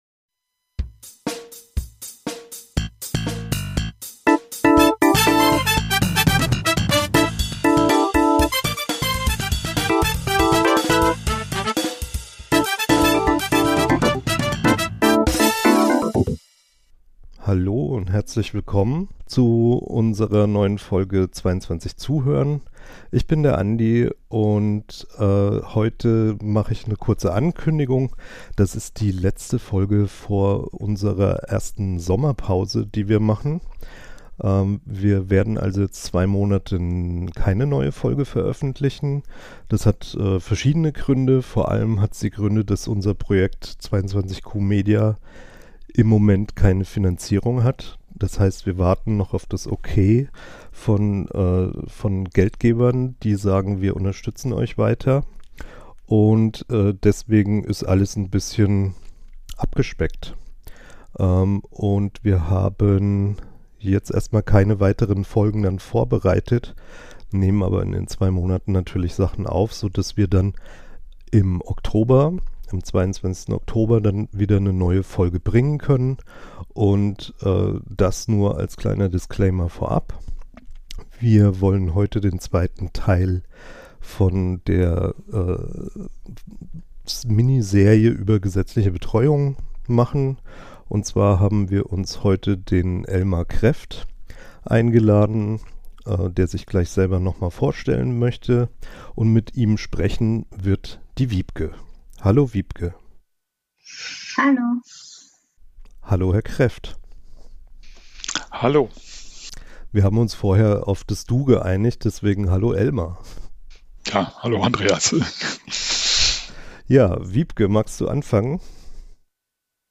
Der Podcast über das Deletions-/Duplikationssyndrom. Von und mit Betroffenen jungen Erwachsenen.